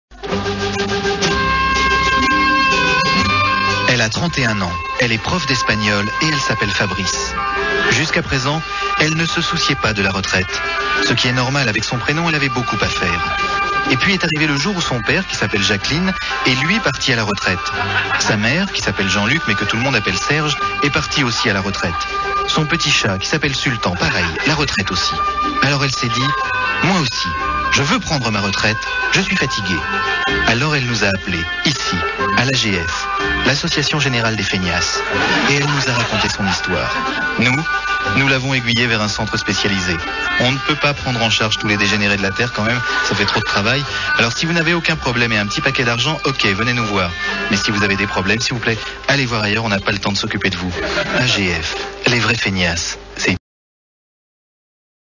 Les Nuls pub AGF assurances 1994 – le Zouzouk sur Europe 1 – Le grenier de la FM